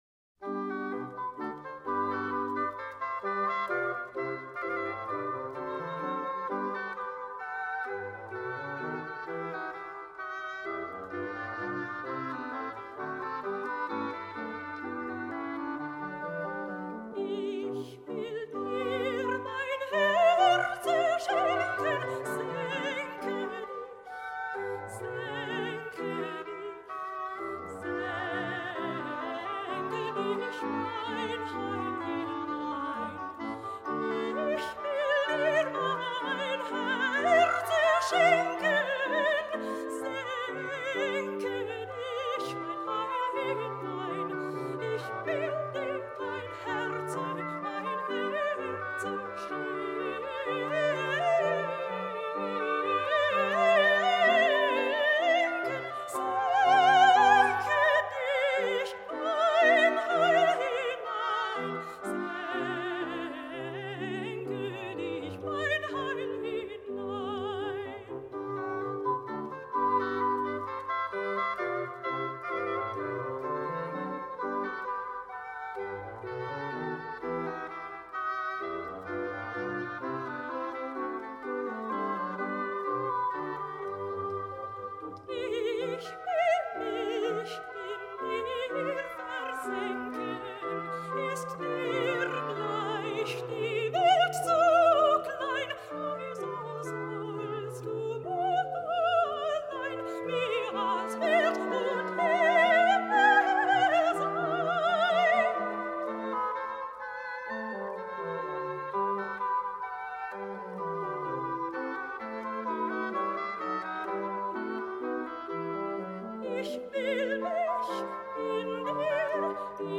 04-arie-sopran.mp3